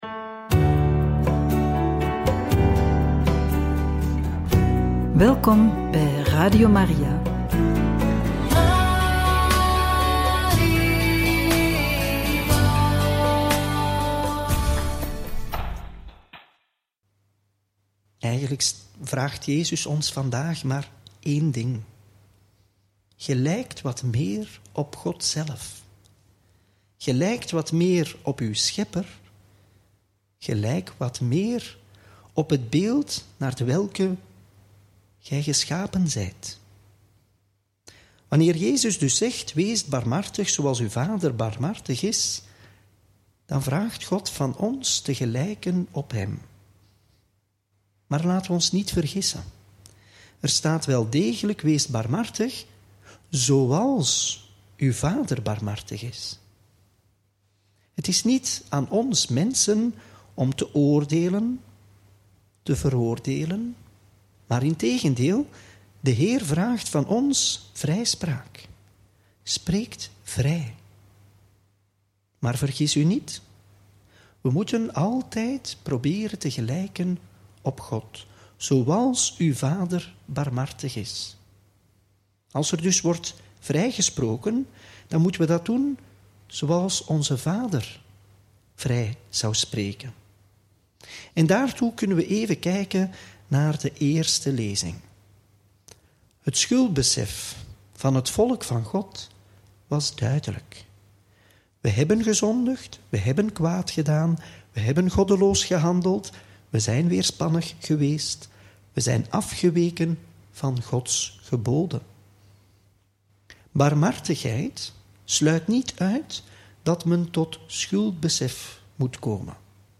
Homilie bij het Evangelie van maandag 17 maart 2025 – Lc 6, 36-38